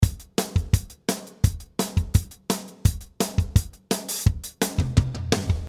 3 minutes of classic pop drum beat in 170 bpm. This loop song was divided to 43 variations and 16 of them are tom fills. This loop song has dirty bass drum to give it a vintage style beat.